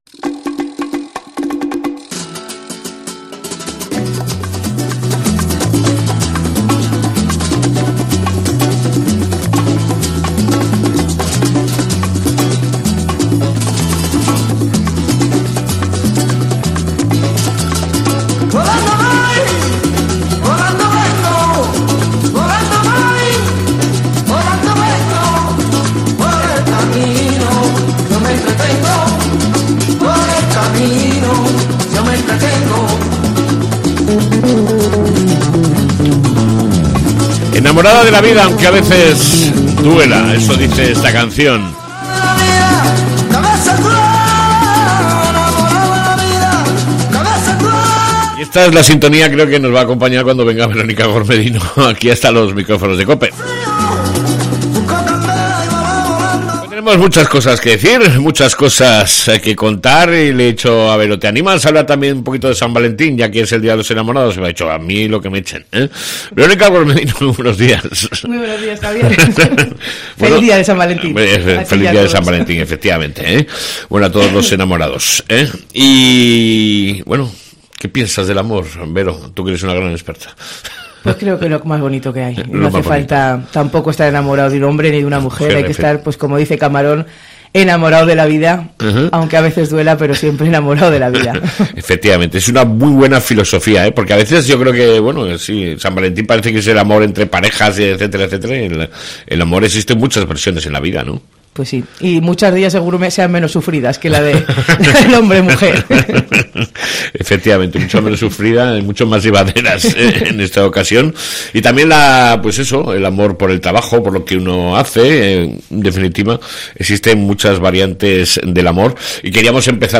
ENTREVISTA SOBRE MEDIO AMBIENTE (LIMPIEZA DEL EBRO-ACTUACIÓN AZUCARERA)